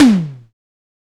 TOM SIMM T00.wav